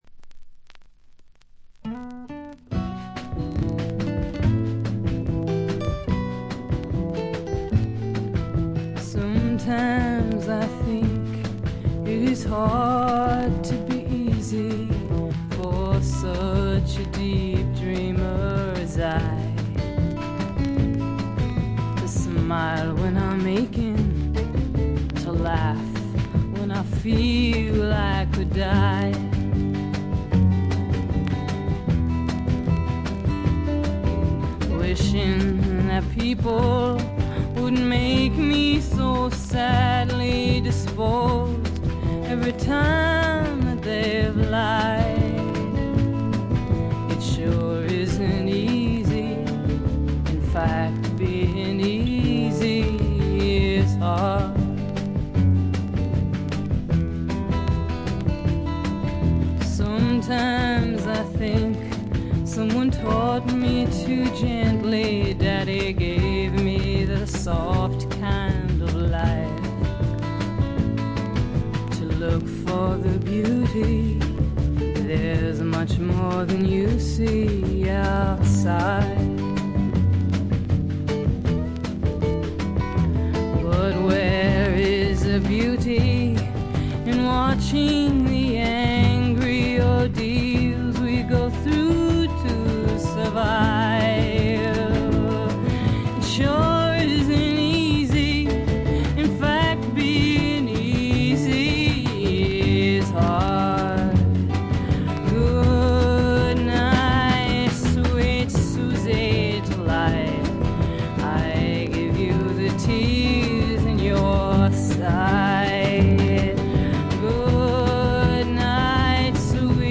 プレスがいまいちなのか、見た目よりバックグラウンドノイズやチリプチは多め大きめ。凶悪なものや周回ノイズはありません。
それを支えるシンプルなバックも見事！の一言。
試聴曲は現品からの取り込み音源です。